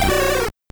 Cri de Caratroc dans Pokémon Or et Argent.